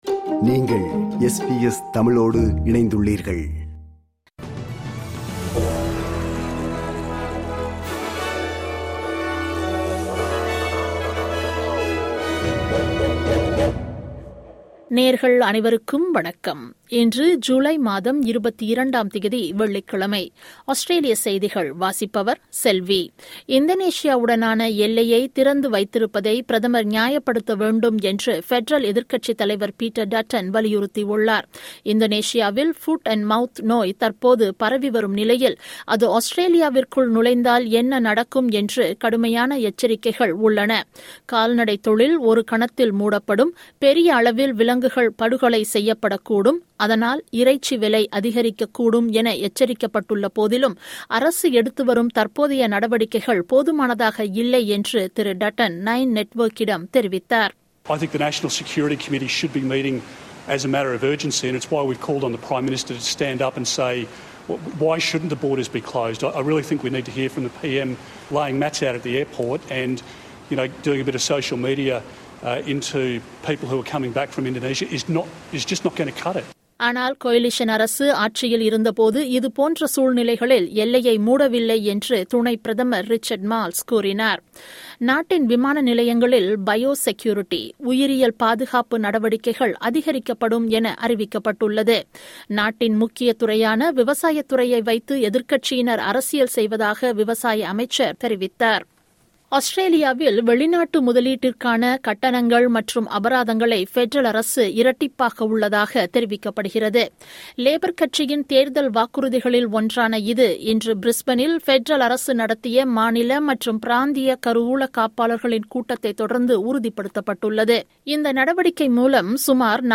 Australian news bulletin for Friday 22 July 2022.